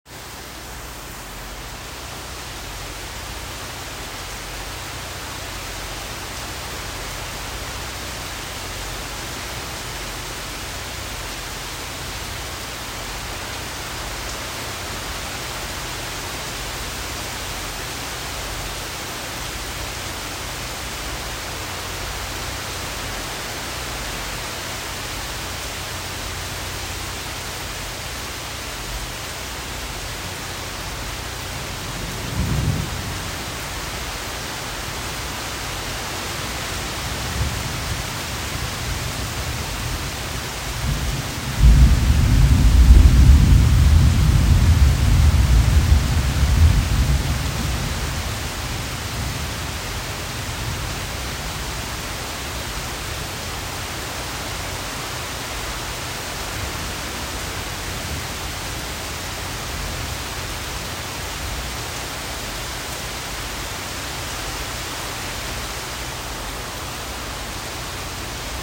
Isla Grande Rain
This is some of the most intense thunder and lighting I witnessed so far on my trip, the audio from my iPhone doesn’t do it justice. But on a small island in the middle of the Caribbean Sea something that initially shocked me awake brought relief from the heat and helped me sleep.
isla_grande_rain.m4a